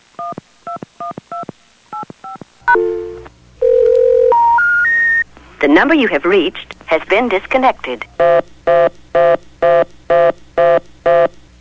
The example waveform includes dial tone, the DTMF tones during dialing, then some speech mixed with a repeating tone.
You can hear that with the ascending tones in this example, which have a bit of tape flutter and thus are not exactly fixed frequency.